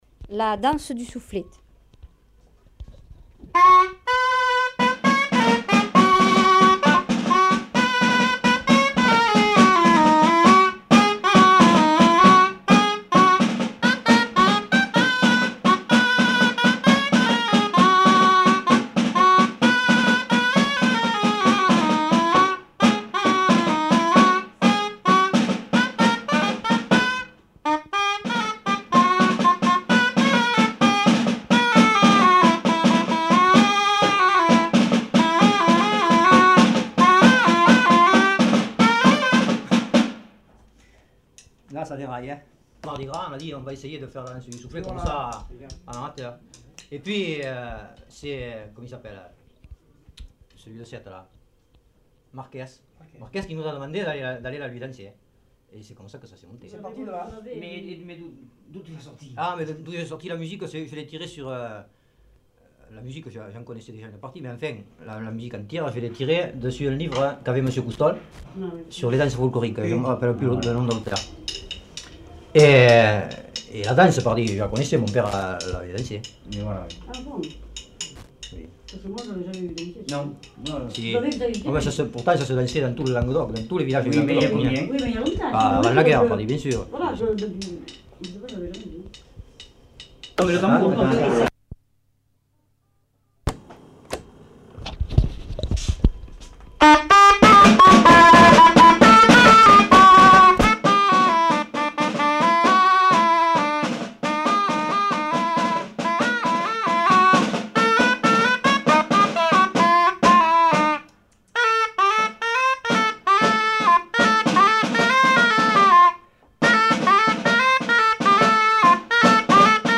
Lieu : Le Pouget
Genre : morceau instrumental
Instrument de musique : aubòi ; tambour
Danse : bufatièra
Notes consultables : Contient des commentaires sur le hautbois.